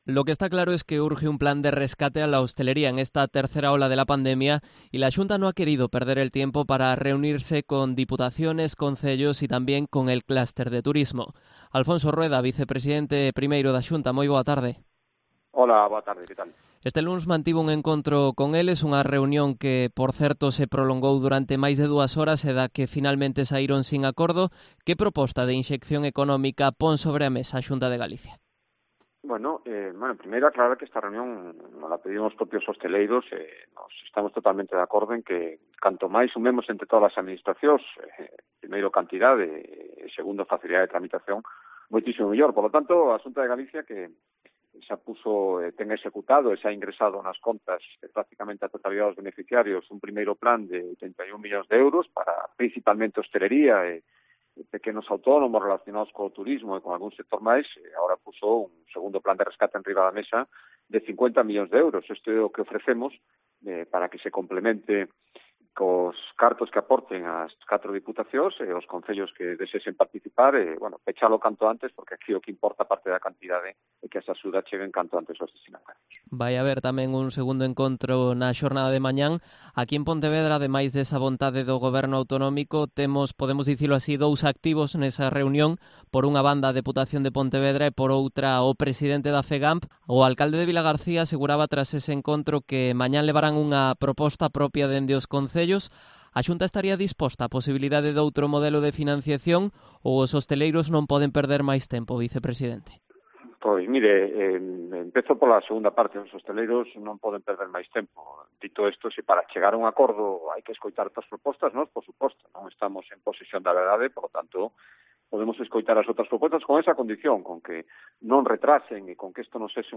Entrevista a Alfonso Rueda, vicepresidente primero de la Xunta